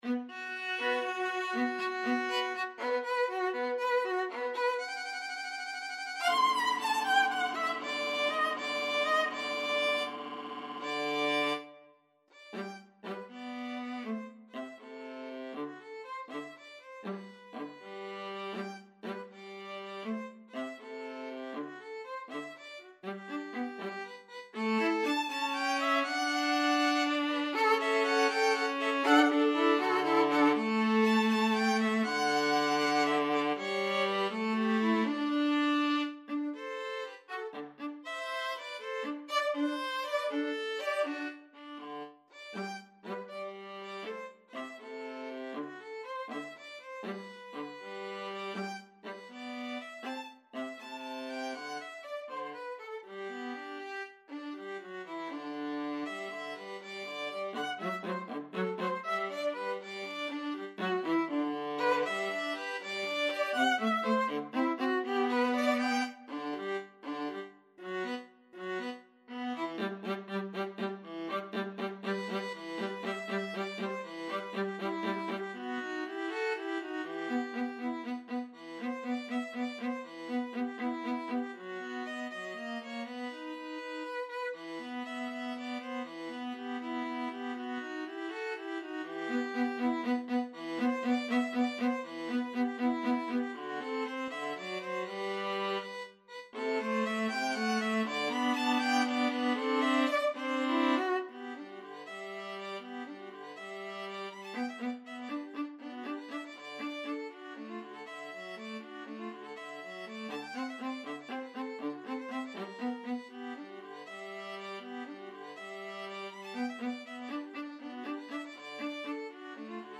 Free Sheet music for Violin-Viola Duet
G major (Sounding Pitch) (View more G major Music for Violin-Viola Duet )
6/8 (View more 6/8 Music)
~ = 100 Allegretto moderato .=80
Classical (View more Classical Violin-Viola Duet Music)